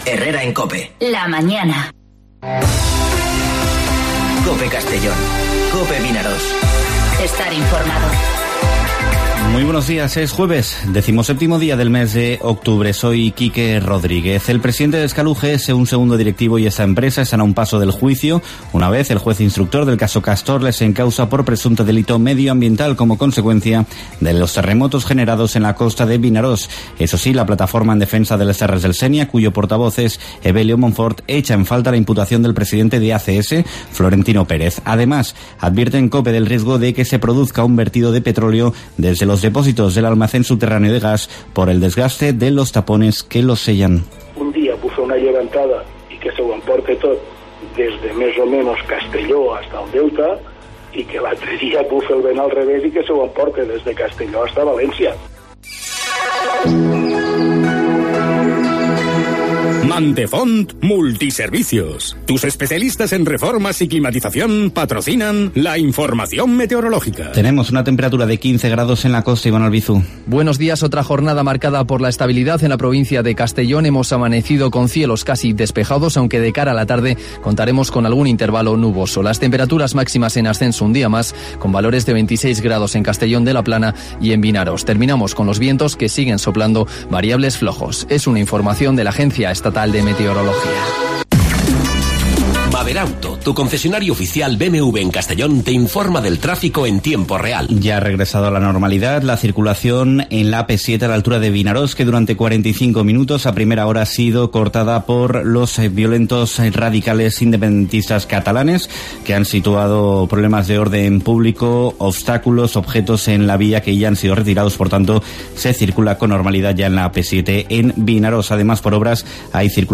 Informativo Herrera en COPE Castellón (17/10/2019)